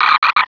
sound / direct_sound_samples / cries / nuzleaf.aif